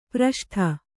♪ praṣṭha